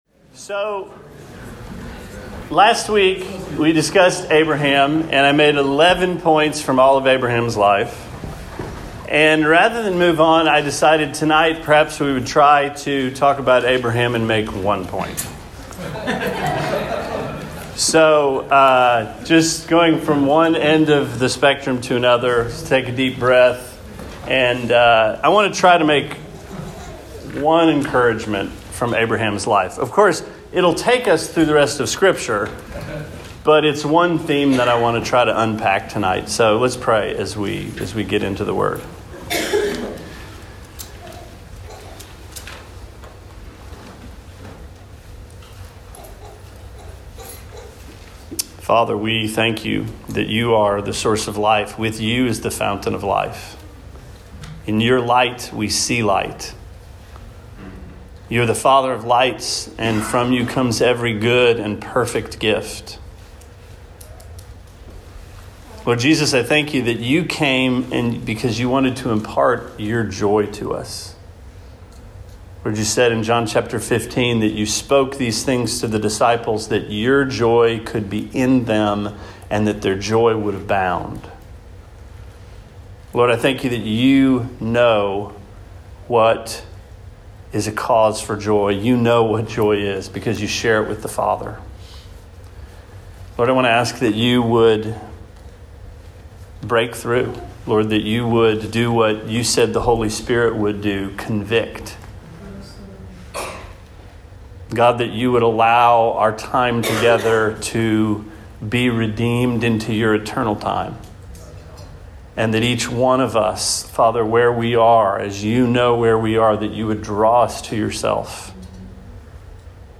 Sermon 03/29: Abraham – Walking with God